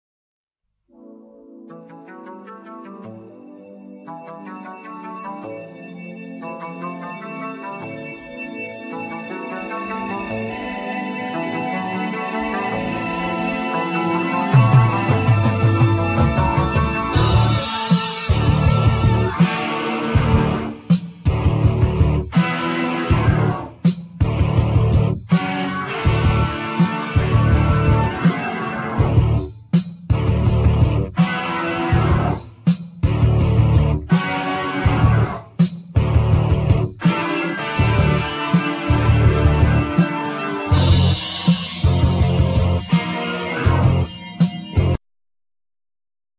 Keyboards
Synthesizers
Flute
Guiatr
Bass
Drums